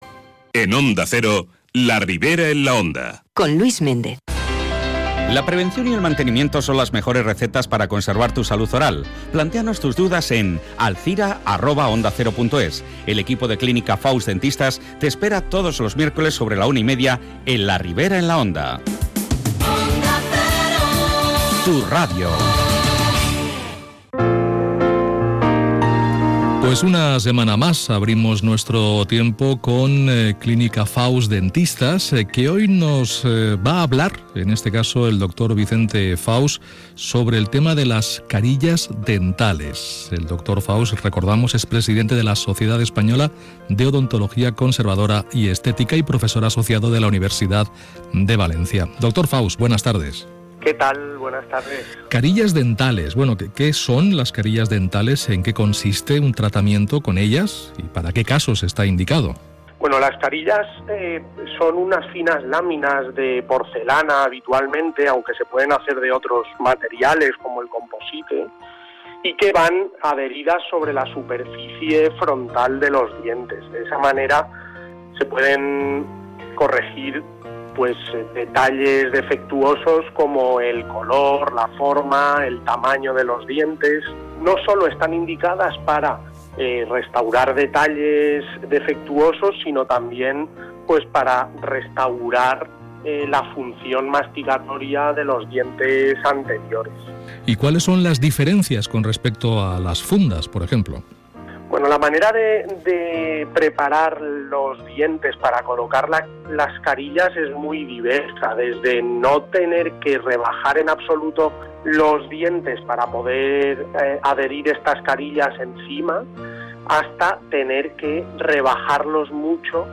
Entrevistas Onda Cero Alzira